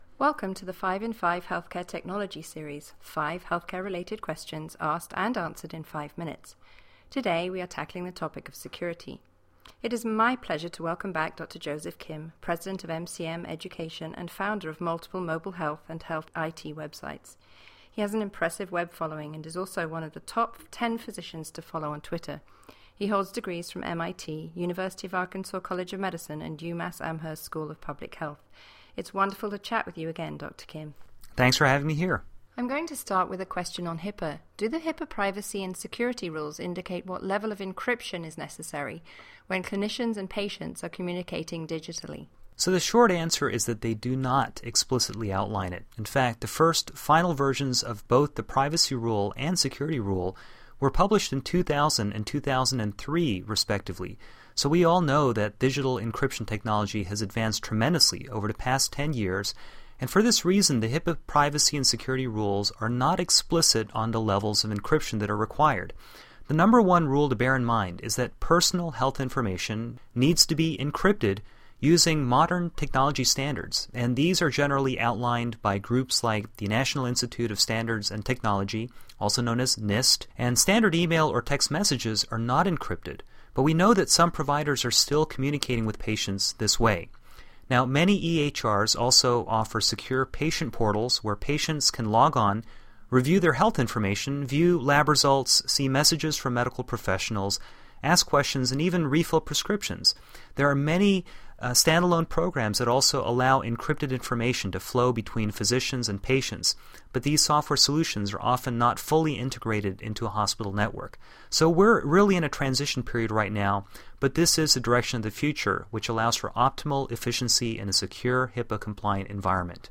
5in5 recordings are sponsored by HP, however opinions on products and services expressed are those of the health-care professional being interviewed.